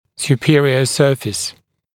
[s(j)uː’pɪərɪə ‘sɜːfɪs][с(й)у:’пиэриэ ‘сё:фис]верхняя поверхность